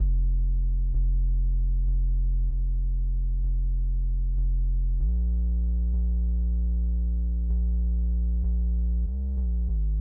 BASS 1.wav